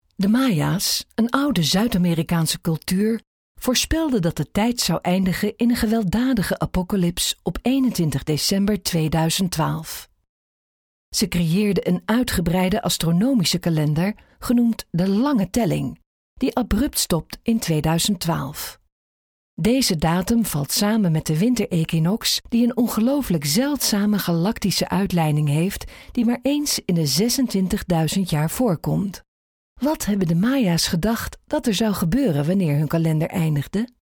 Dutch female voice over
Sprechprobe: Sonstiges (Muttersprache):